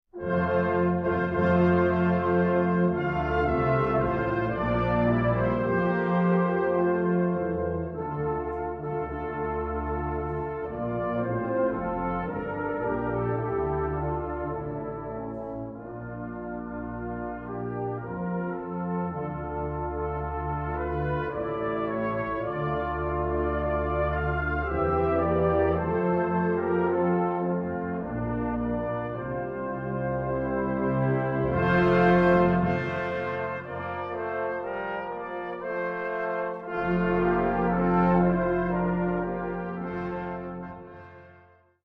Répertoire pour Brass band - Brass Band